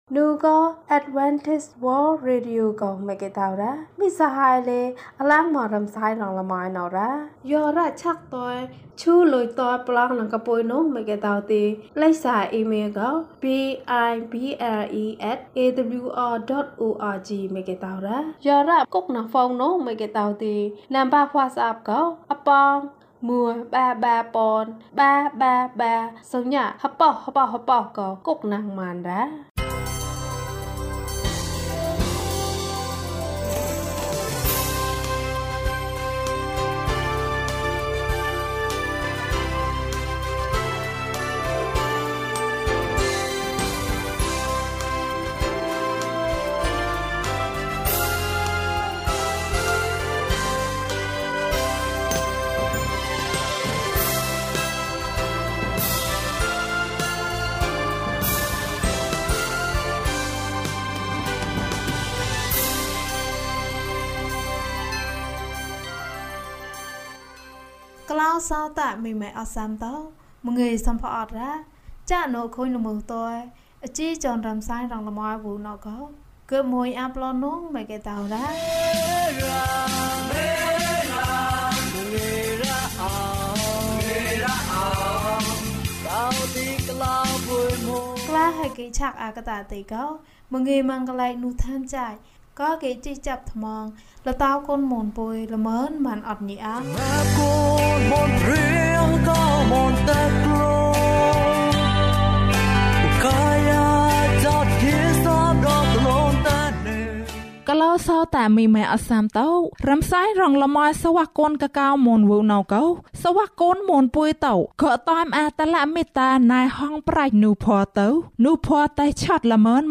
အချစ်ရဲ့အကောင်းဆုံး။ ကျန်းမာခြင်းအကြောင်းအရာ။ ဓမ္မသီချင်း။ တရားဒေသနာ။